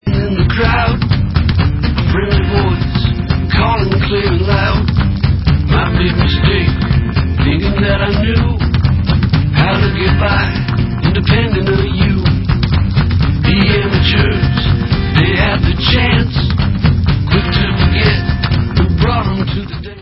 americká indie-rocková kapela